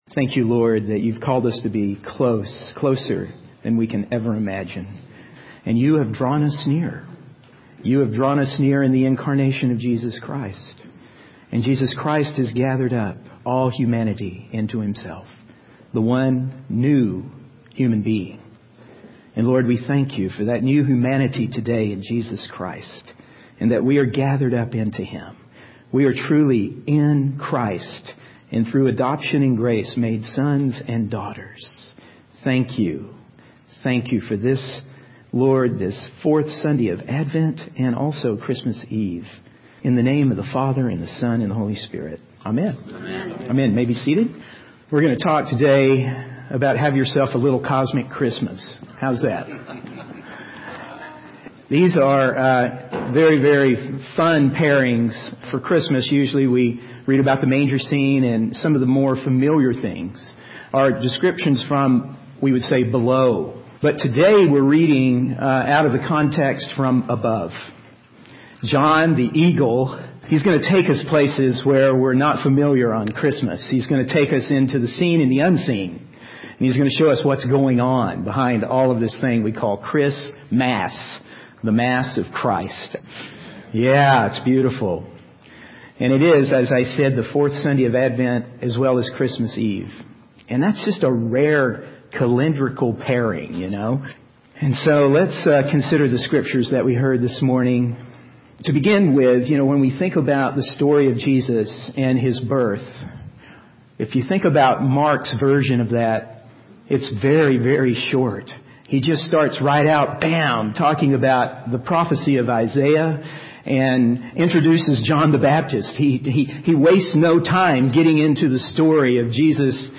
In this sermon, the preacher explores the unseen story behind the rebellion against God and the cosmic realities surrounding the birth of Jesus Christ.